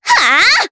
Rodina-Vox_Attack3_jp.wav